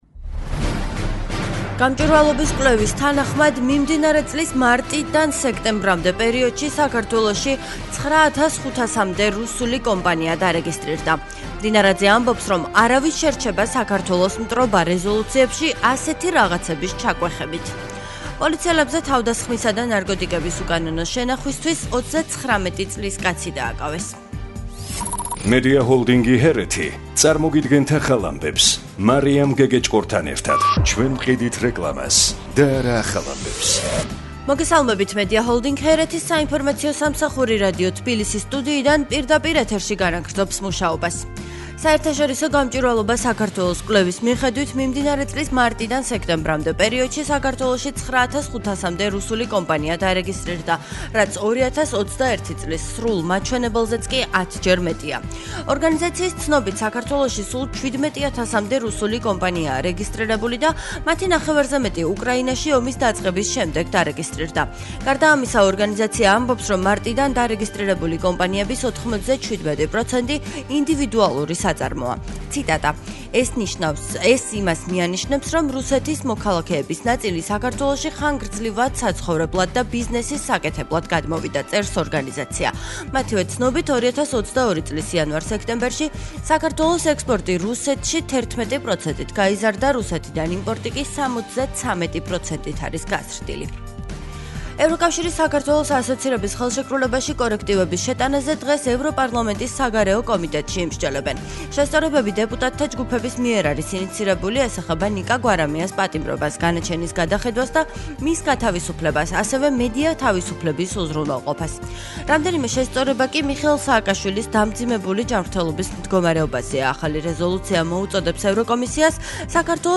ახალი ამბები 12:00 საათზე